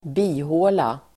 Uttal: [²b'i:hå:la]